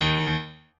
piano4_36.ogg